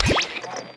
Store Trade Enter Sound Effect
store-trade-enter.mp3